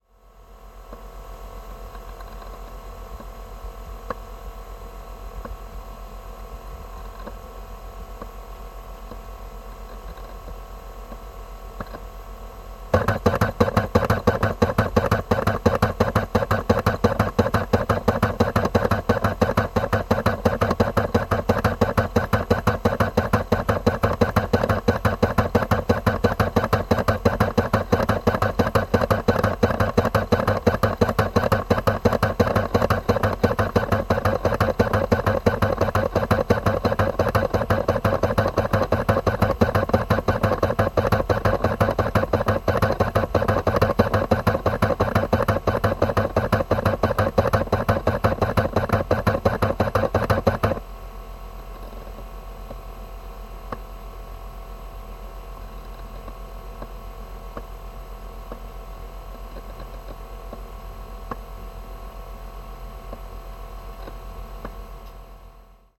随机的 "计算机驱动器采取CD DVD和弹出拉出
描述：电脑驱动器取CD DVD并弹出拉出.flac
标签： 电脑 CD 光驱 DVD 弹出
声道立体声